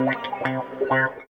110 GTR 3 -R.wav